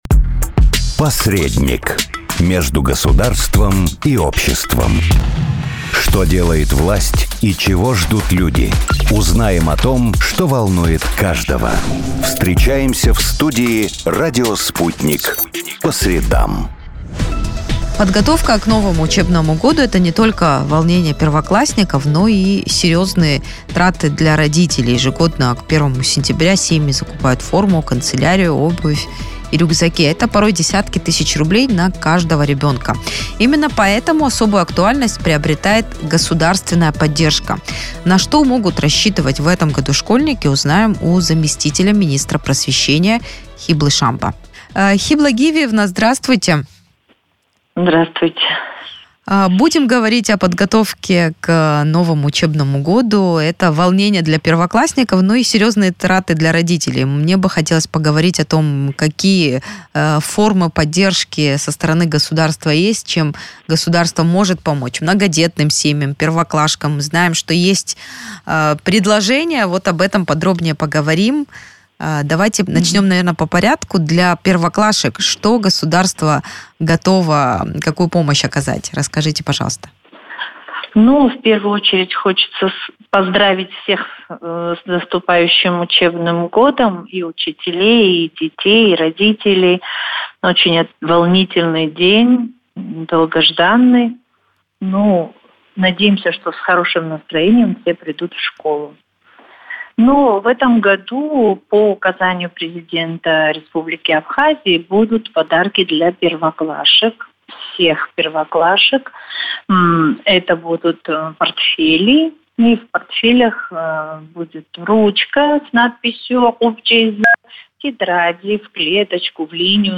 На какую помощь со стороны государства в этом году могут рассчитывать школьники, в интервью радио Sputnik рассказала замминистра просвещения Хибла Шамба.